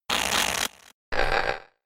Game: Pokemon SFX Gen 5 - Attack Moves - BLK, WHT, BLK2, WHT2 (DS) (2020)